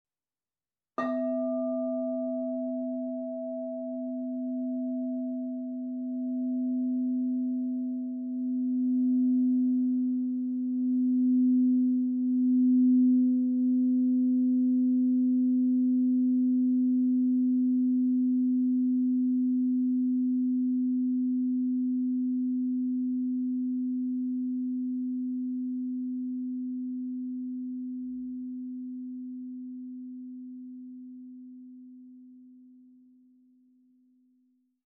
Meinl Sonic Energy 12" Planetary Tuned Crystal Singing Bowl, Sonne 126,22 Hz (PCSB12S)